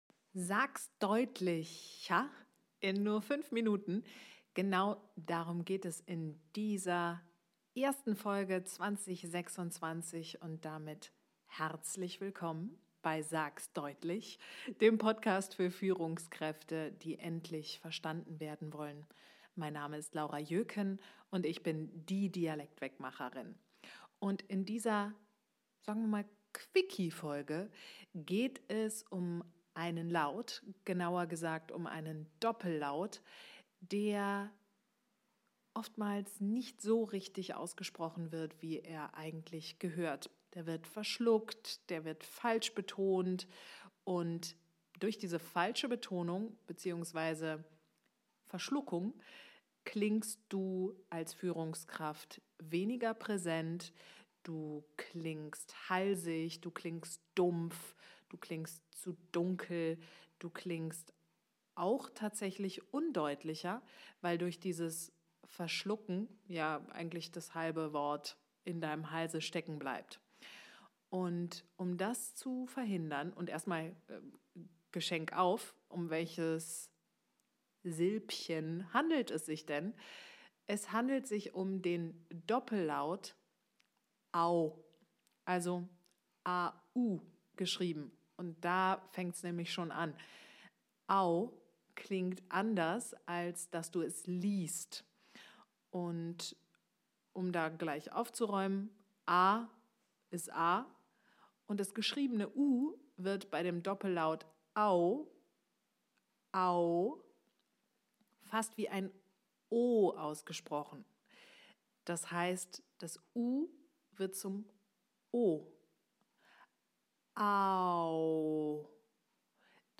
warum AU immer eine hörbare Bewegung ist
Fünf Minuten Training.